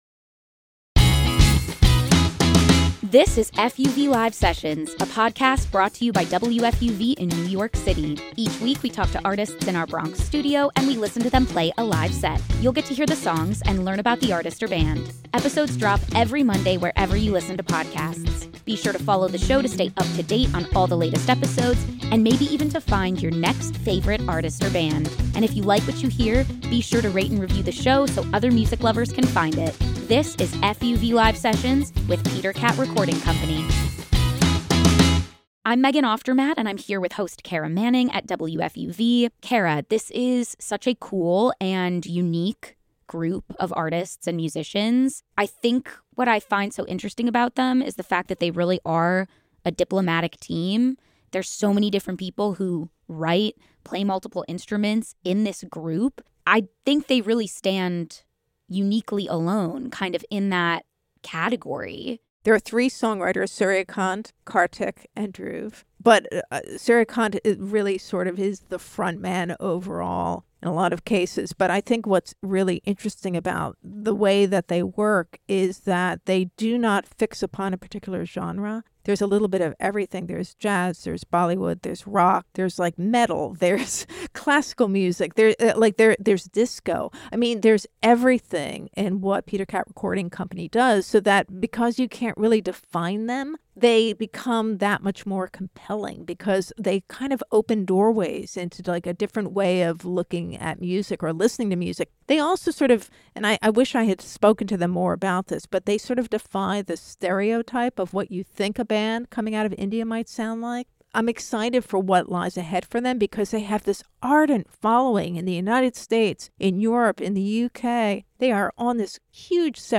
The globetrotting, genre-defying band